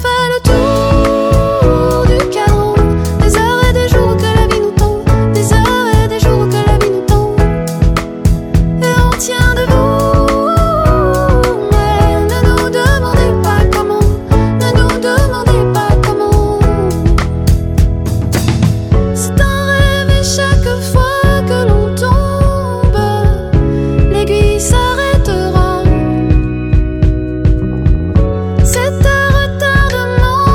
Chansons francophones